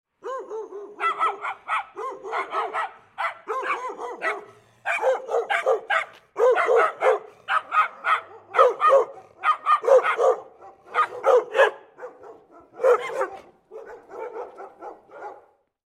Aggressive Guard Dog Barking In Backyard – Sound Effec
Frantic Guard Dog Barking – Sharp Aggressive Canine Alert Sound Effect
This recording features high-pitched, rapid, and frantic dog barking in an outdoor environment, delivering an alert and aggressive guard dog sound effect.
Frantic-guard-dog-barking-sharp-aggressive-canine-alert-sound-effect.mp3